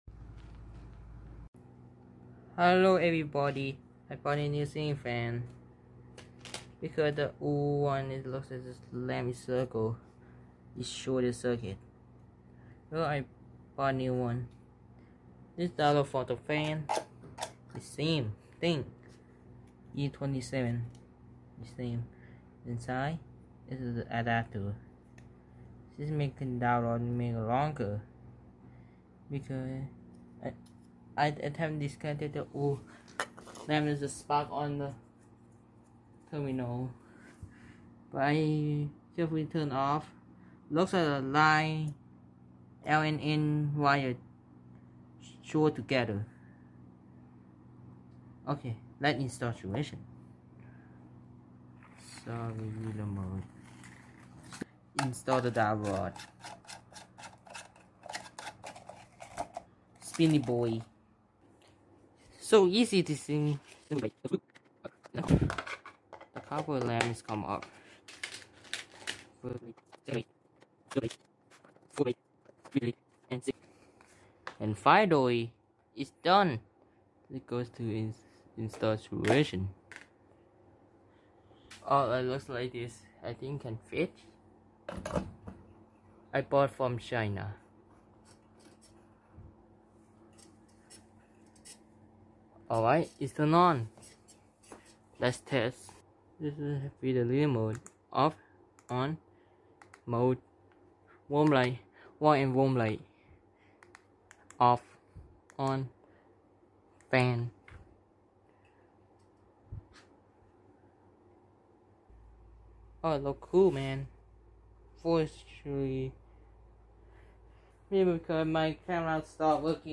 Ceiling Fan E27 LLOL Sound Effects Free Download